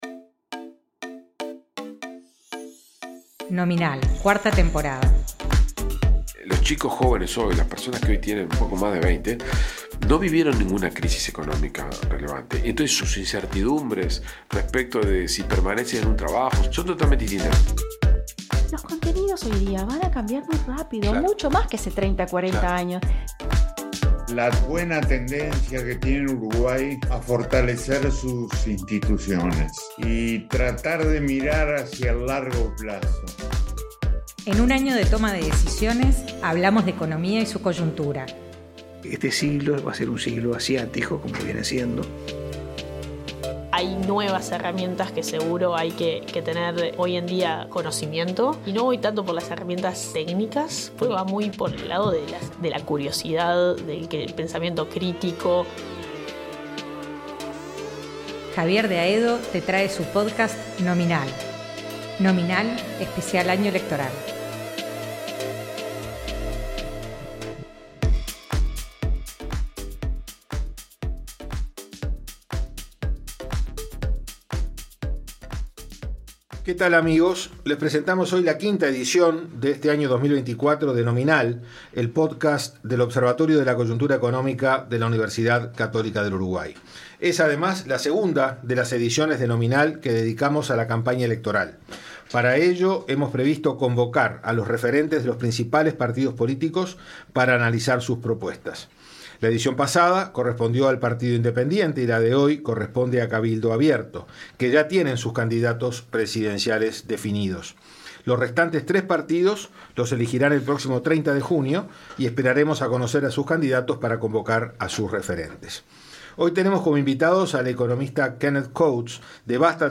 conversó con los economistas